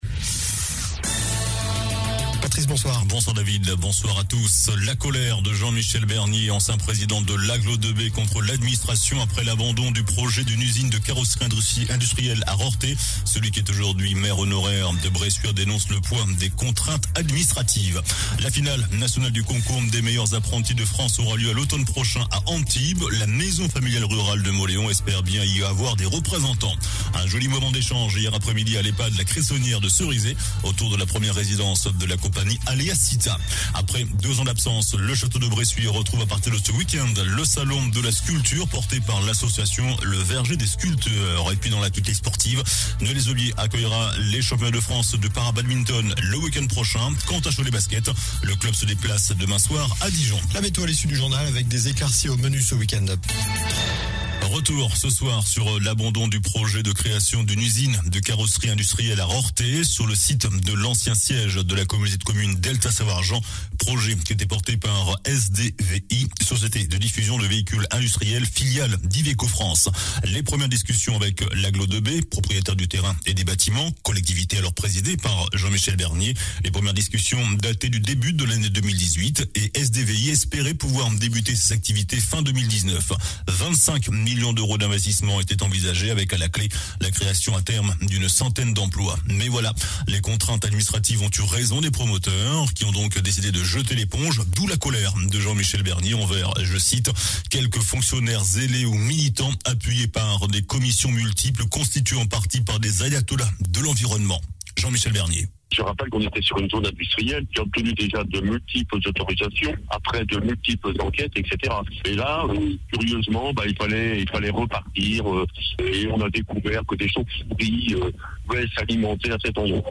JOURNAL DU VENDREDI 18 MARS ( SOIR )